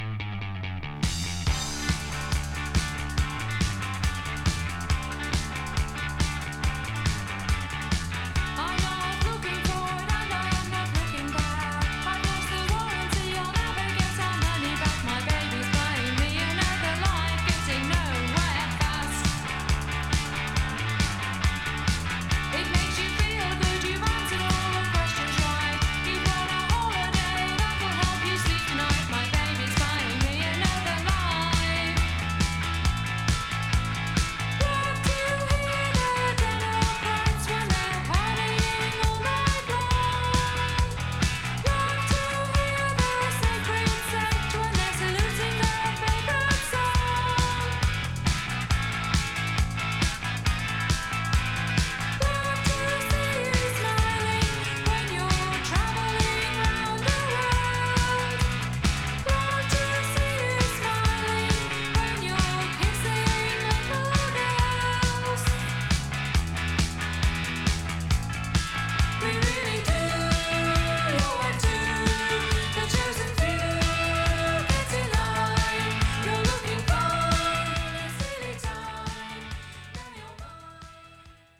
80's英国ガールインディー・ポップの代表的バンド。